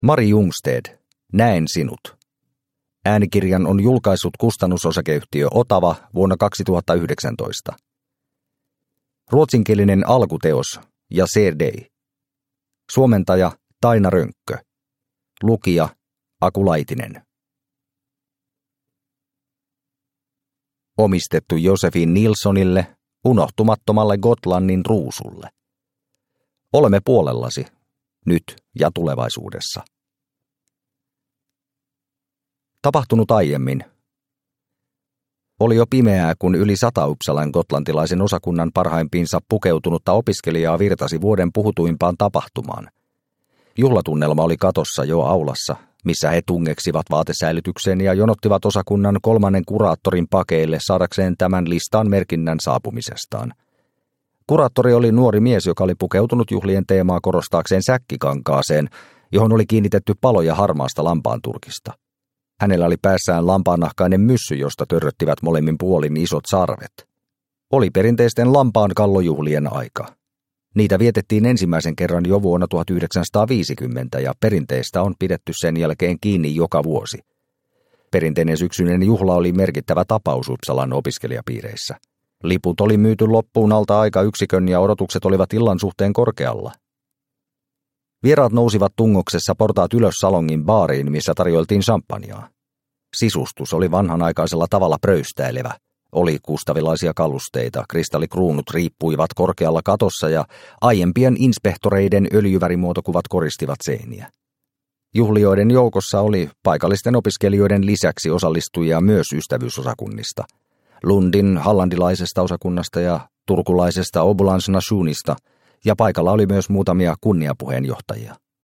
Näen sinut – Ljudbok – Laddas ner